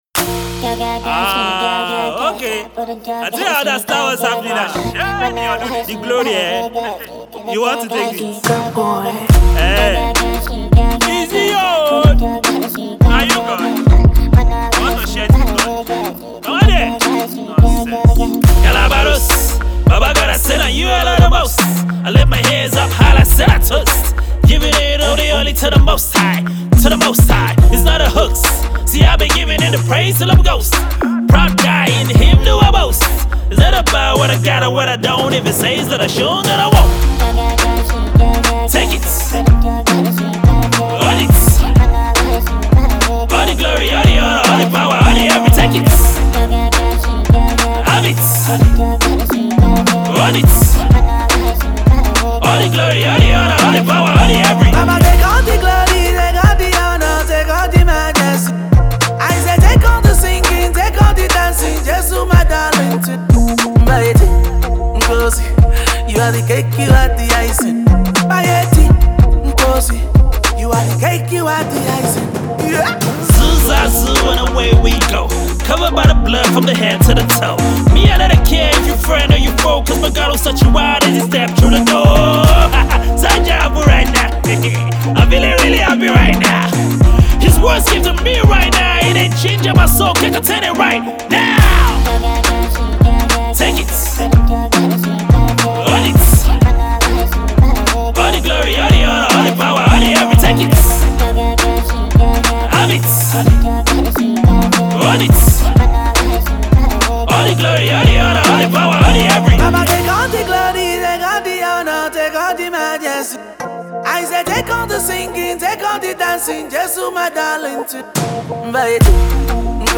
infectious tune